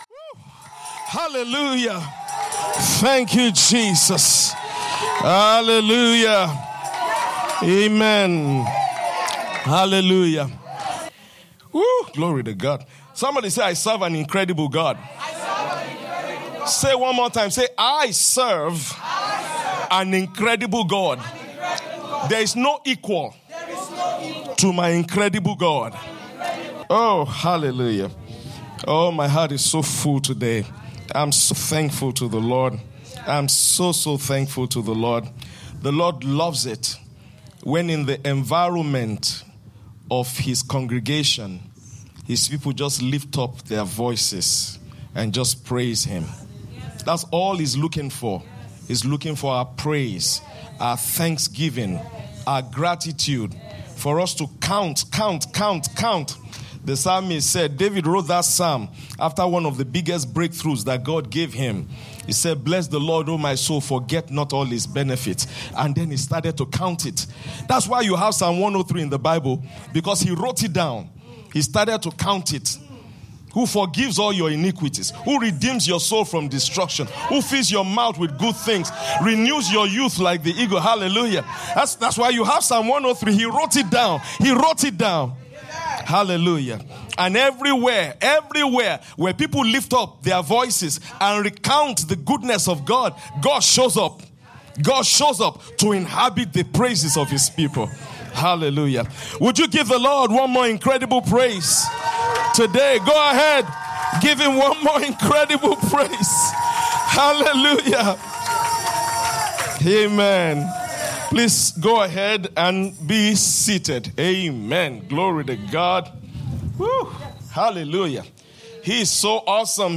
2025 Thanksgiving Service